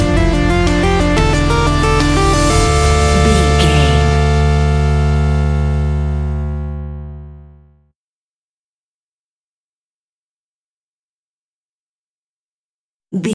Aeolian/Minor
scary
ominous
dark
haunting
eerie
drums
synthesiser
ticking
electronic music